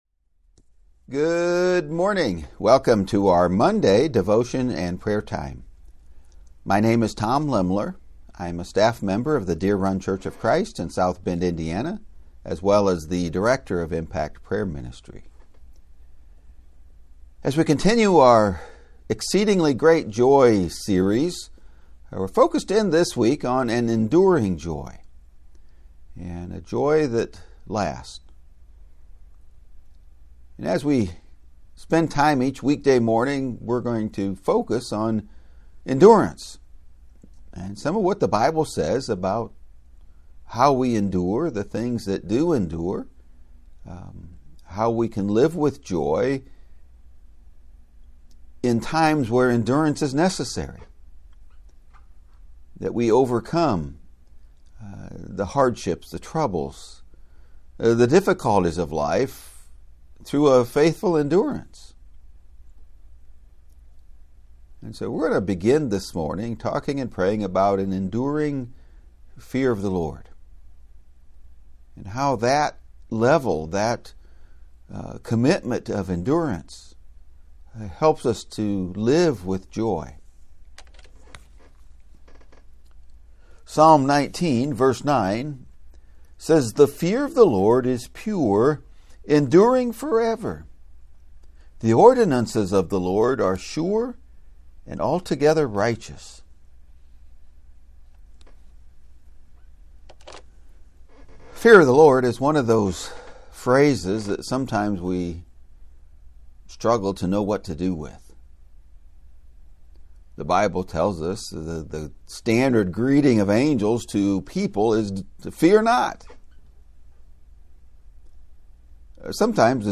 In prayer